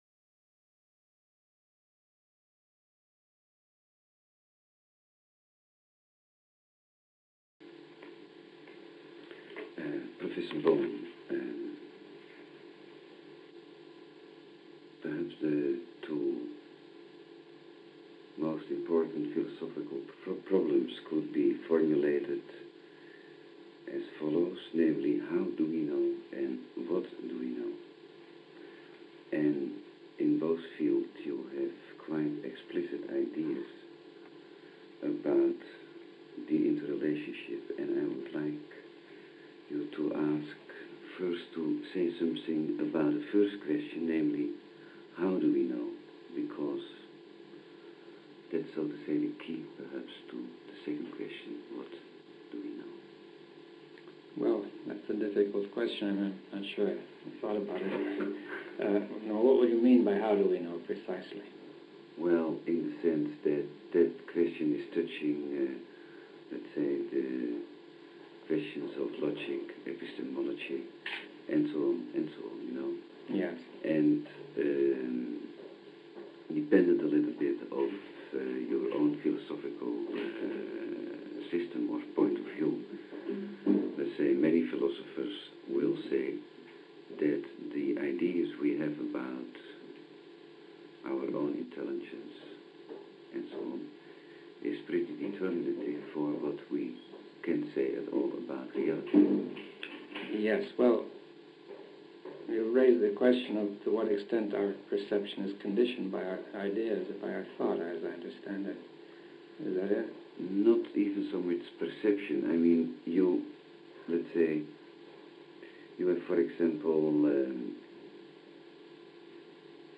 Interview_David_Bohm_1983 - Fons Elders
Interview_David_Bohm_1983.mp3